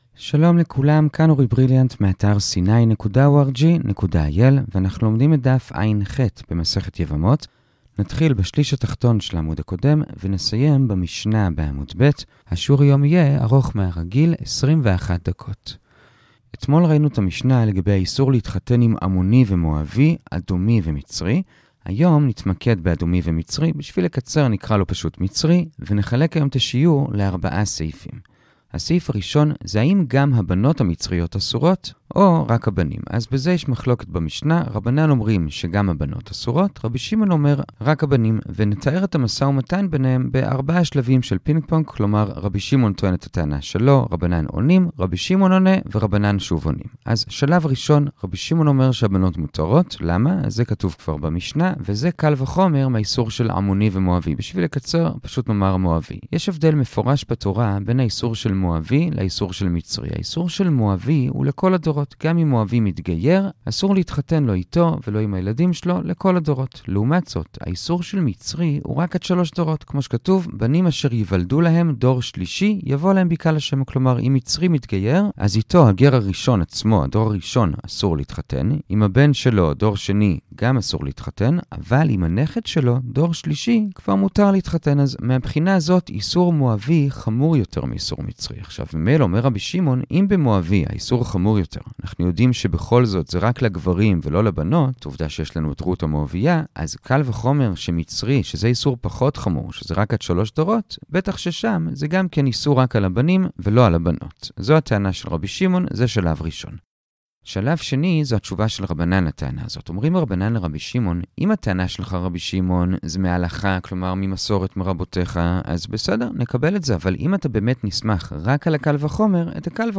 הדף היומי - יבמות עח - הדף היומי ב15 דקות - שיעורי דף יומי קצרים בגמרא